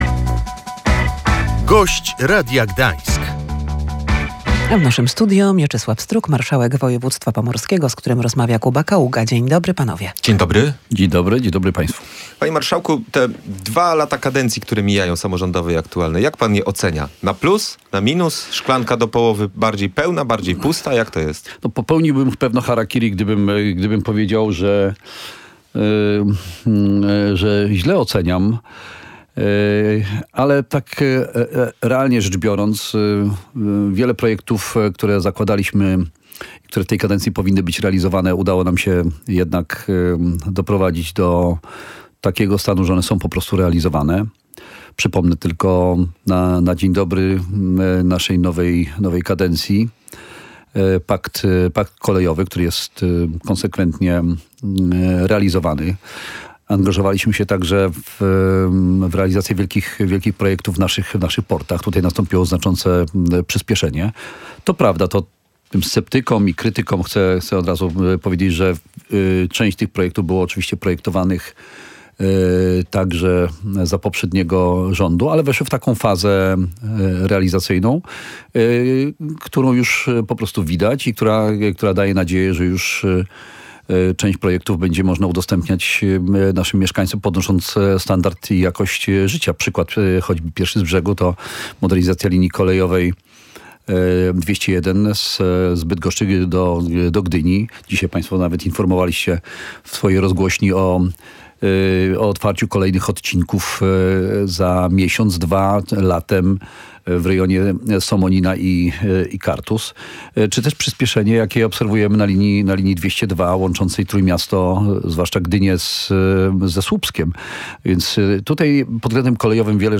Pakt kolejowy dla Pomorza, inwestycje w portach z zaangażowaniem samorządu, modernizacja linii kolejowej 201 z Bydgoszczy do Gdyni czy przyspieszenie prac na linii kolejowej łączącej Gdynię ze Słupskim to kluczowe projekty tej kadencji samorządu, które są realizowane – ocenił w audycji „Gość Radia Gdańsk” Mieczysław Struk, marszałek województwa pomorskiego.